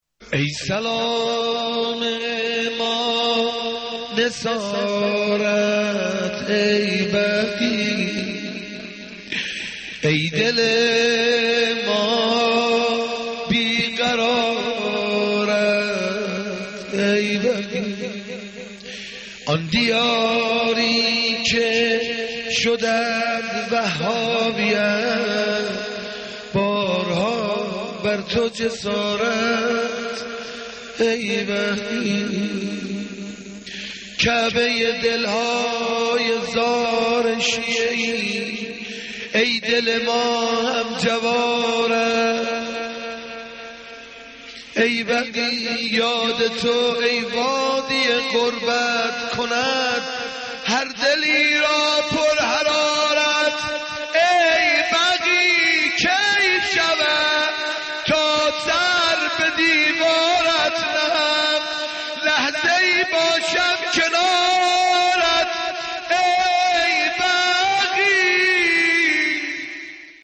مرثیه سالروز تخریب قبور ائمه بقیع